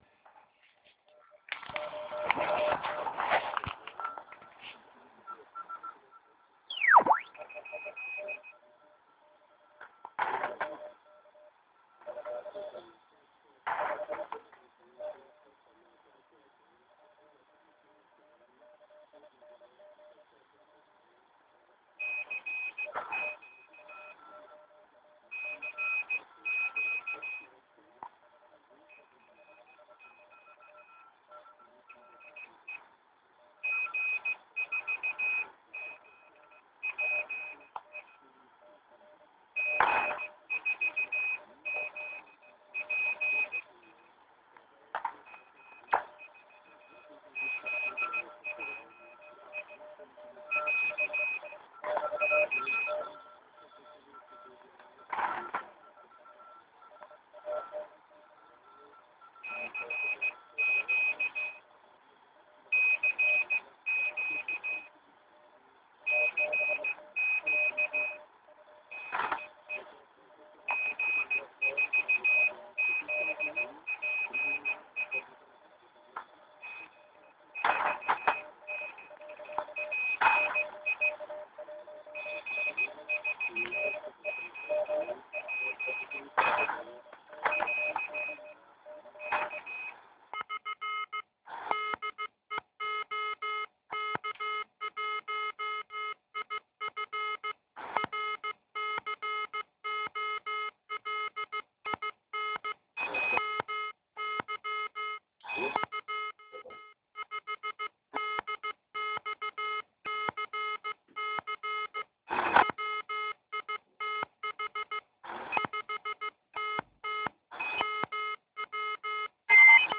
Musel jsem to nahrát na mobilní telefon. Ta nahrávka je děsná, ale je to jasný důkaz.